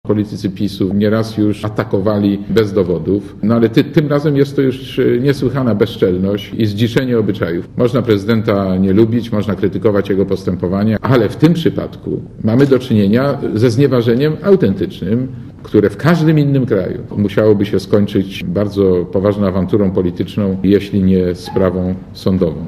Mówi Marek Borowski "Gazeta Wyborcza" opublikowała w piątek wypowiedź Kaczyńskiego, który powiedział, że prezydent nie powinien jechać do Moskwy na obchody 60-lecia zakończenia II wojny światowej .